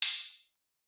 TM88 - OPEN HAT (5).wav